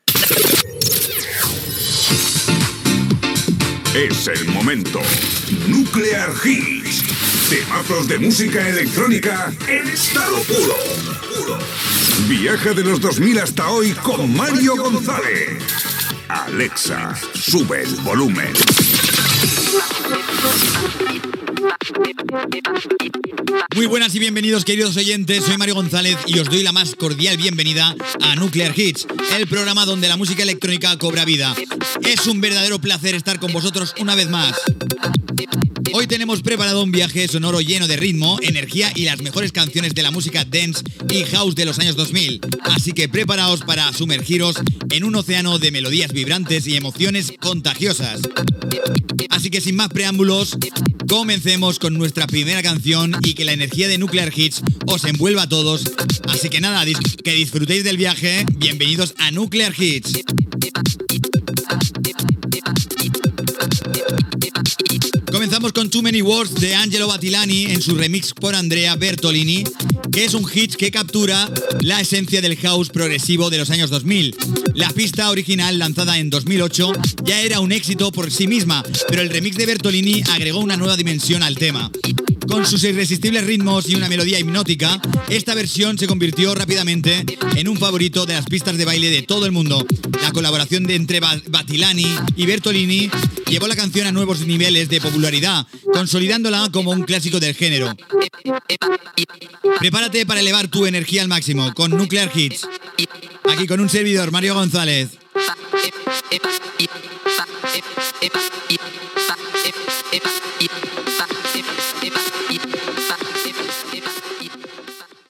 Careta, presentació inicial i tema musical
Musical